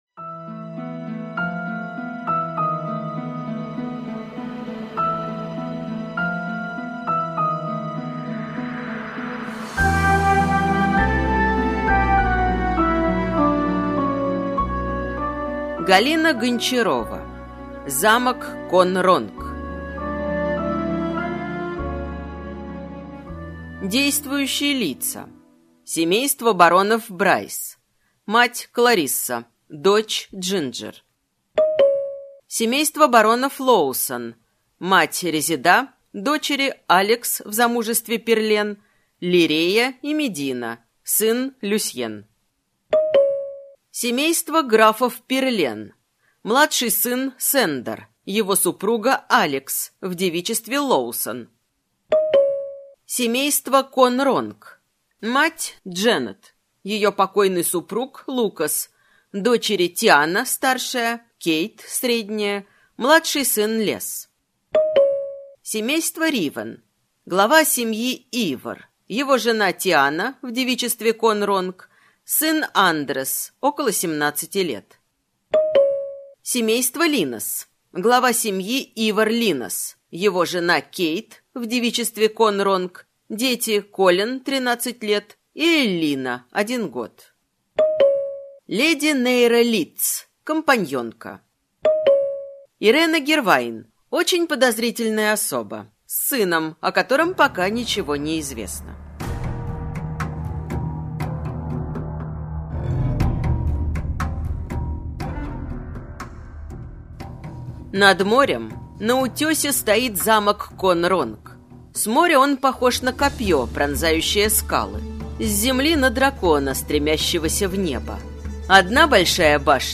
Аудиокнига Замок Кон’Ронг - купить, скачать и слушать онлайн | КнигоПоиск